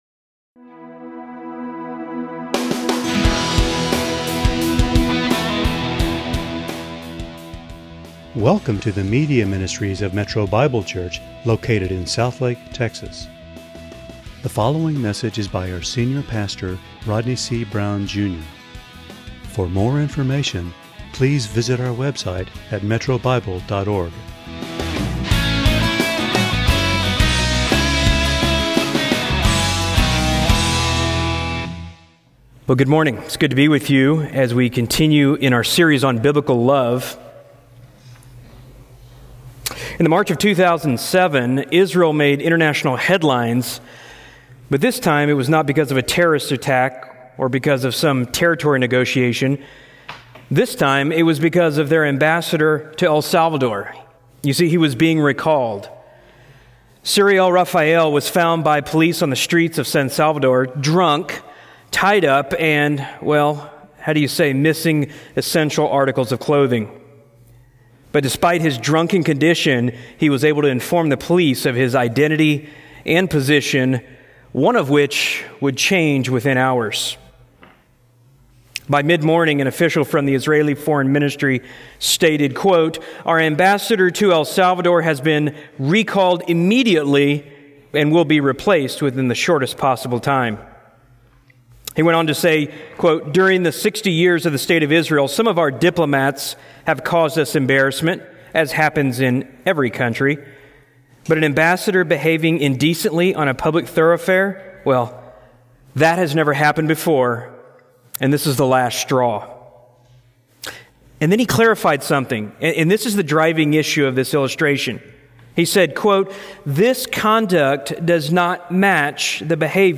× Home About sermons Give Menu All Messages All Sermons By Book By Type By Series By Year By Book How Do We Love as a Church?